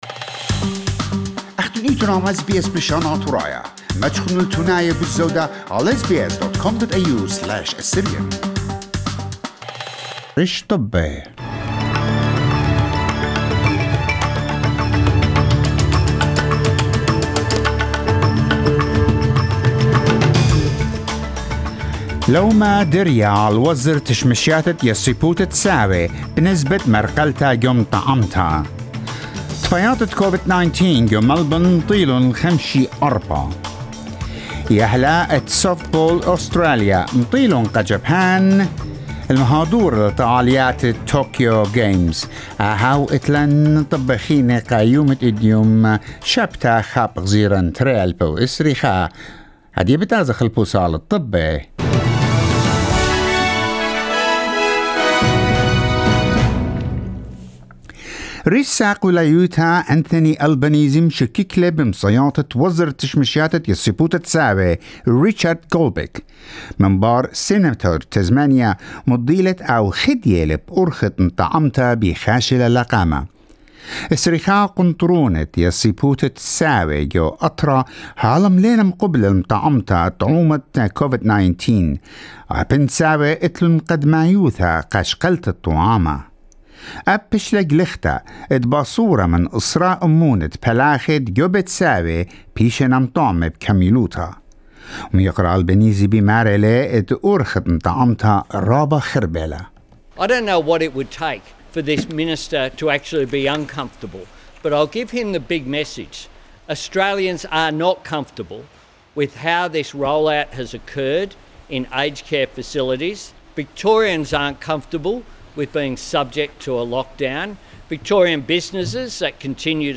SBS NEWS BULLETIN TUESDAY 1/6/2021